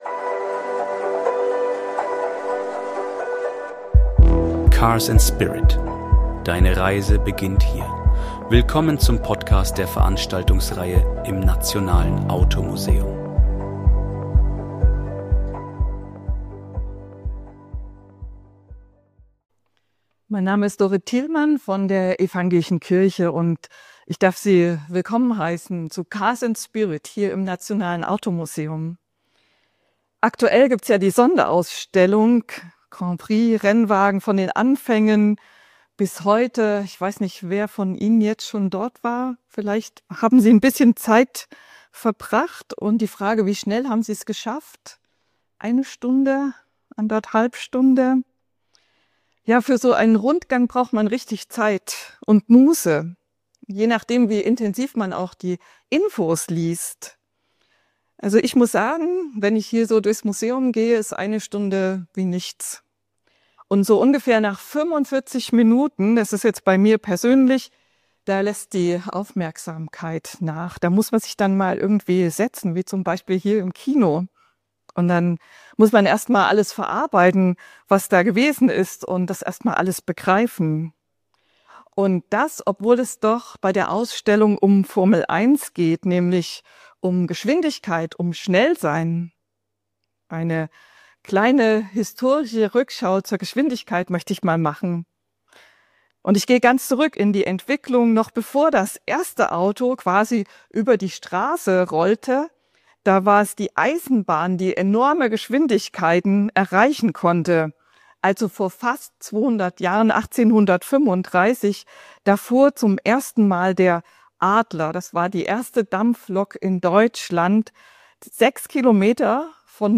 Die Veranstaltungsreihe im Nationalen Automuseum.